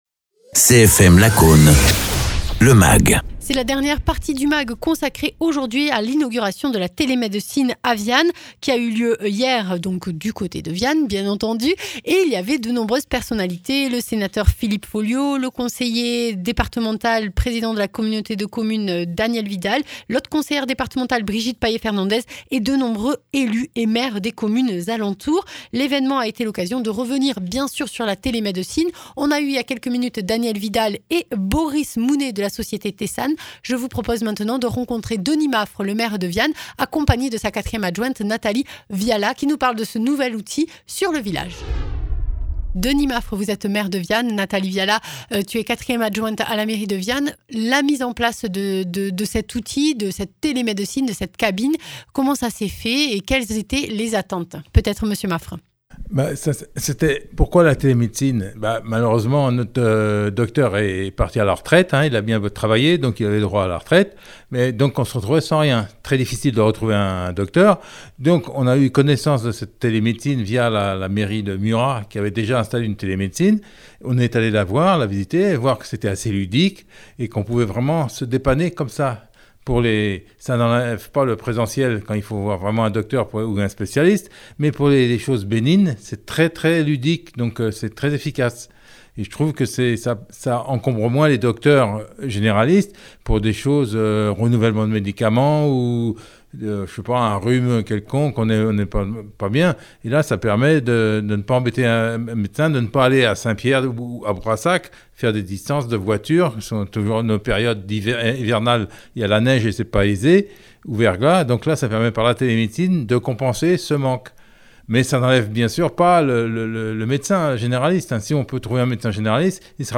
Interviews
Invité(s) : Denis Maffre, maire de Viane-Pierre-Ségade et Nathalie Viala, 4ème adjointe de la commune